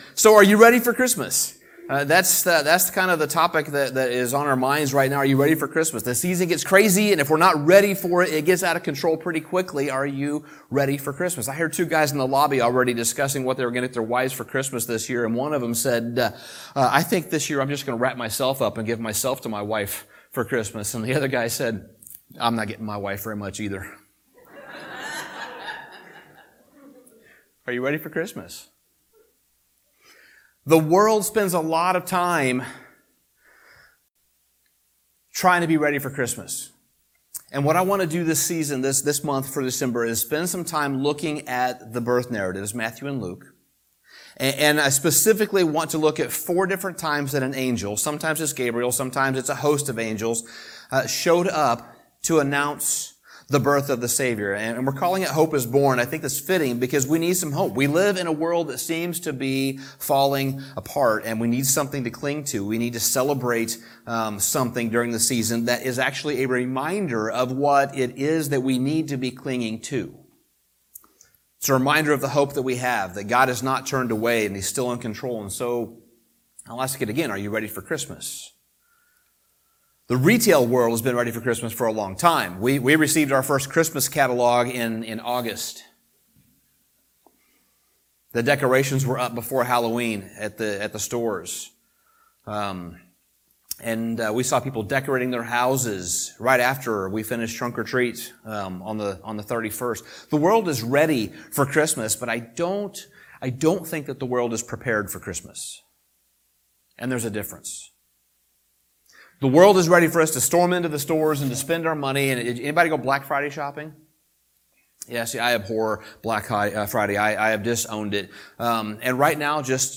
Sermon Summary The first of the angelic appearances in the nativity narrative was to an unlikely individual.